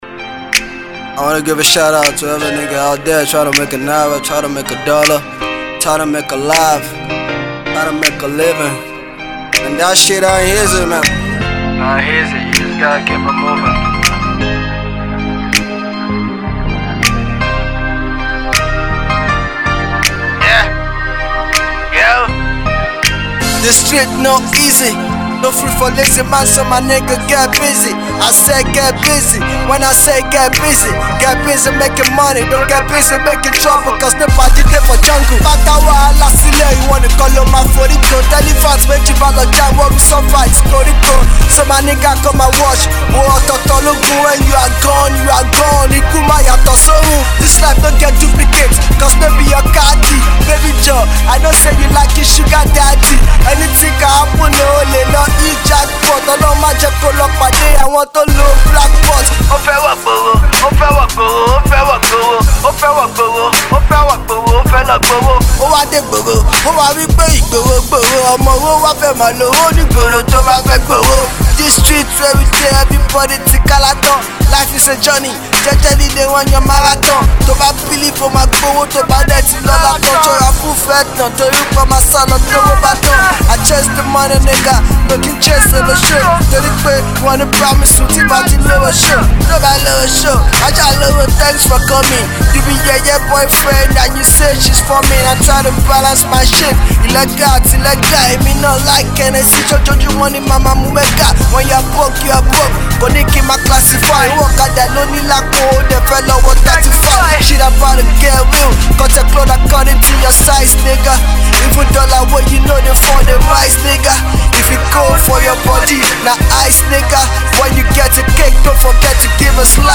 hardcore beat